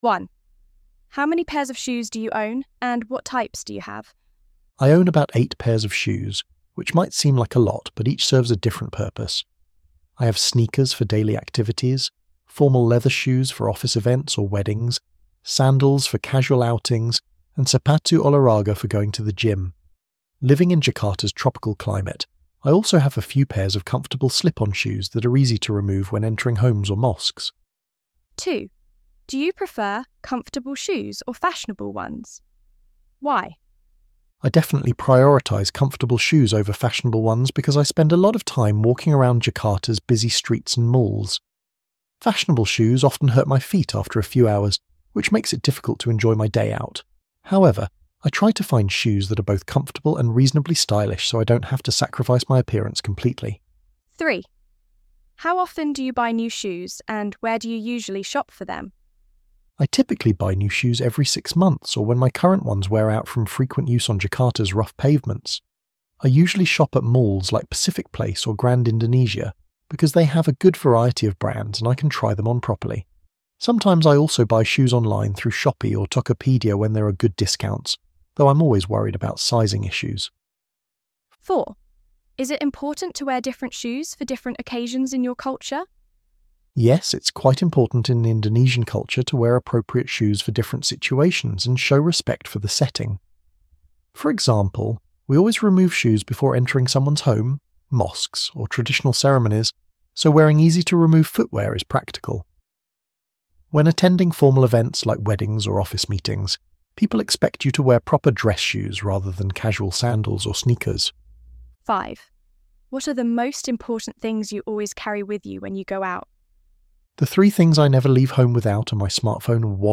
ElevenLabs_12_September.mp3